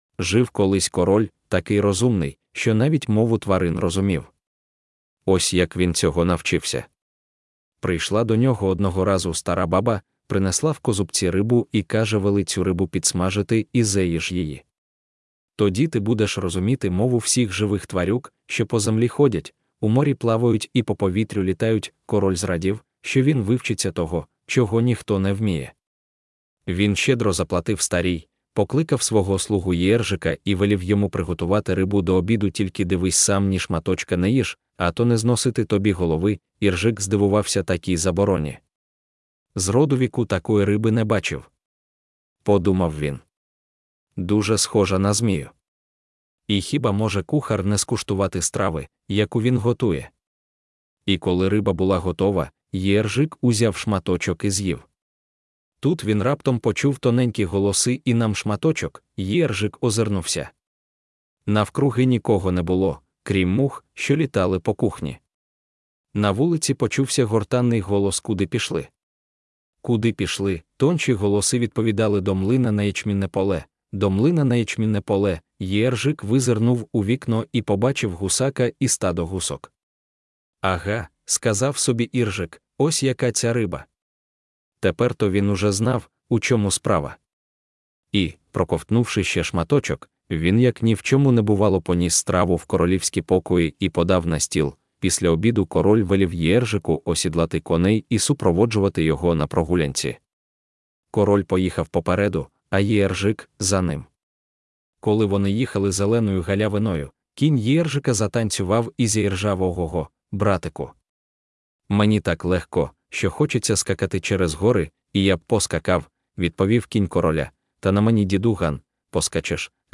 Аудіоказка Золотоволоска